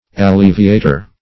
Alleviator \Al*le"vi*a`tor\, n.